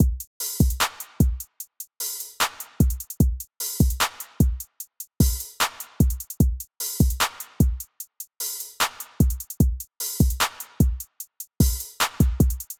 KIT LOOPS TRAP 150 BPM – Kit de Pontos
1_Top_Loop_150.wav